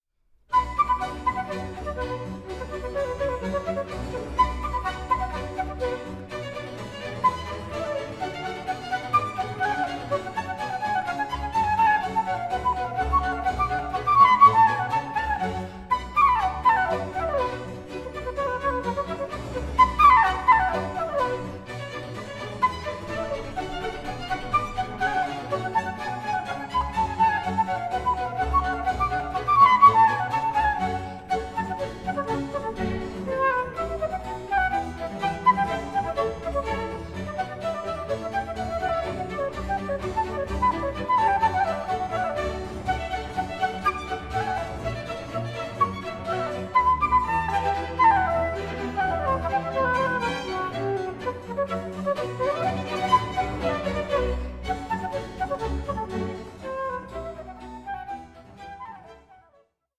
For Flute, Strings & Basso Continuo